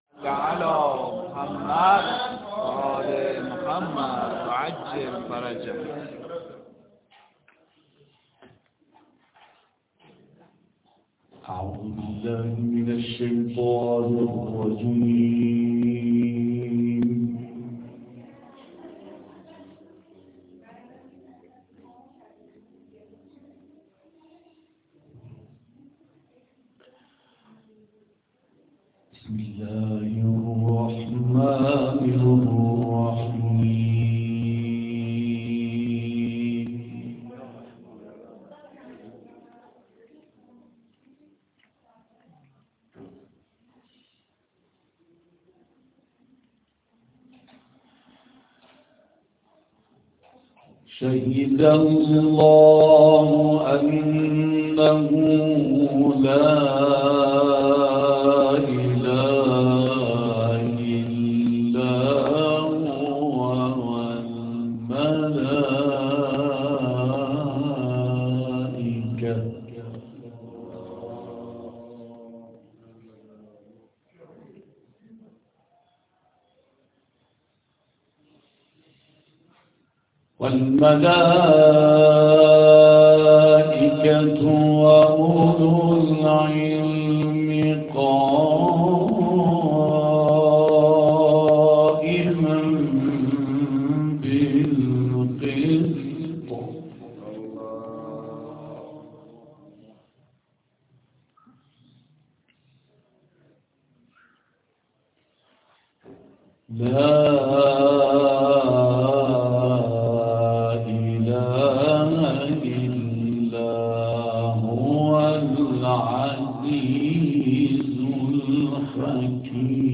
او در مسجد قمر بنی‌هاشم(ع) واقع در بلوار فردوس غرب،سازمان برنامه آیات 18 تا 30 سوره آل عمران را برای مستمعان تلاوت کرد.